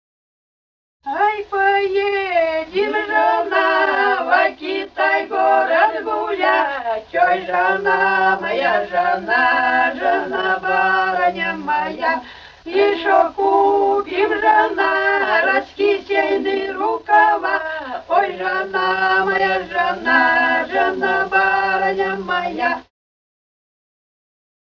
с. Урыль Катон-Карагайского р-на Восточно-Казахстанской обл.